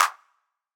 ORG Clap.wav